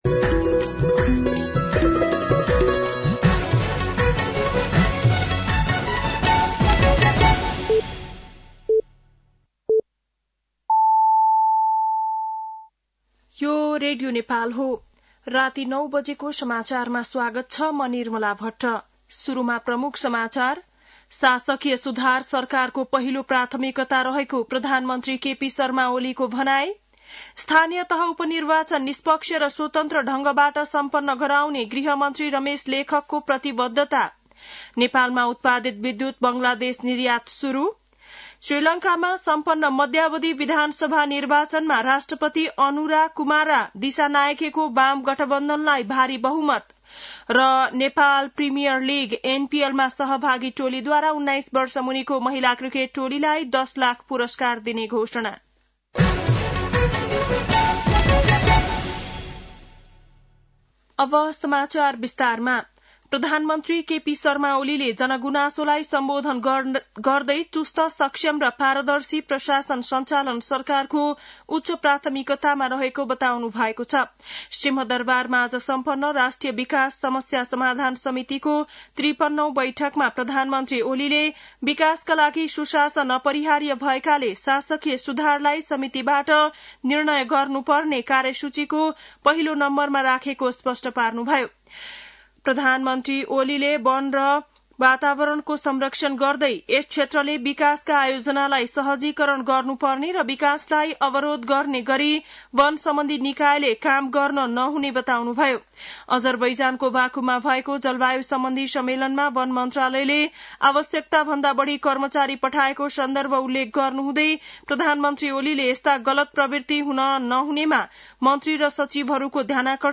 बेलुकी ९ बजेको नेपाली समाचार : १ मंसिर , २०८१
9-PM-Nepali-NEWS-7-30.mp3